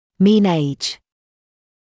[miːn eɪʤ][миːн эйдж]средний возраст